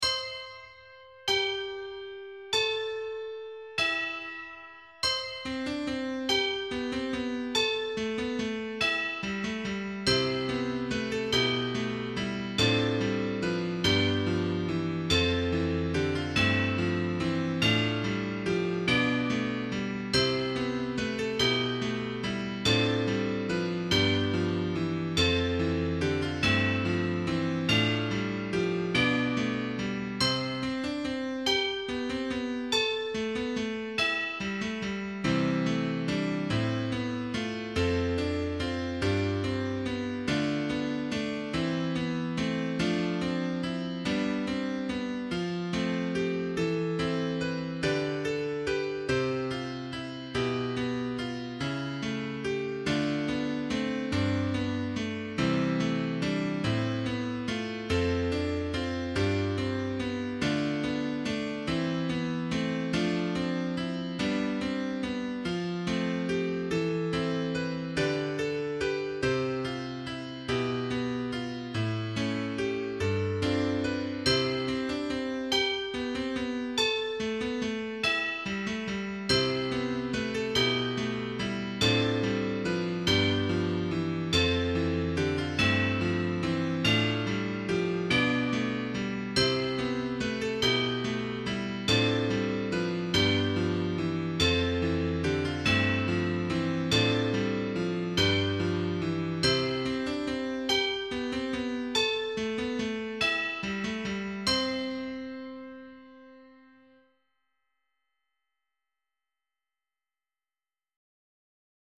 A computer generated sound file is included.
Hand Bells/Hand Chimes , Primary Children/Primary Solo